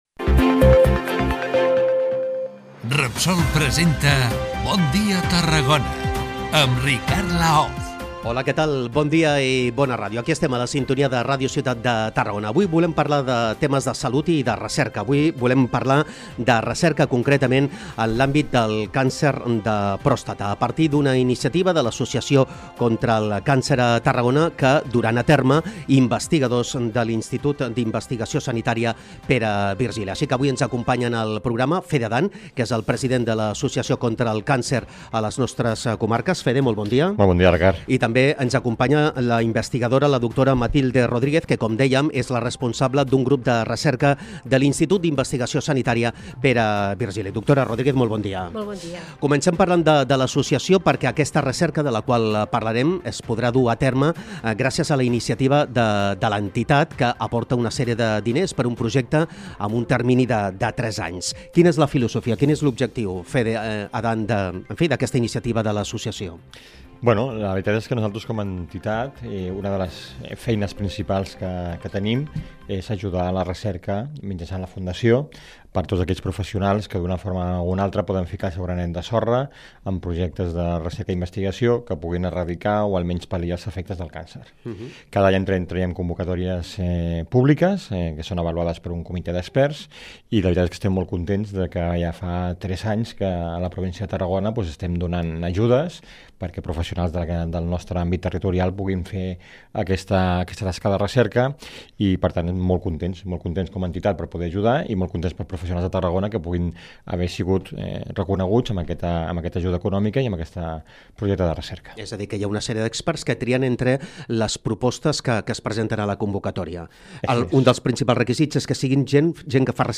Diu que l’objectiu és avançar en recerca i per això ja porten tres edicions impulsant projectes d’investigadors tarragonins. L’altre gran objectiu és oferir serveis gratuïts a pacients i familiars a través de professionals que poden millorar la seves condicions de vida. MIRA I ESCOLTA AQUÍ L’ENTREVISTA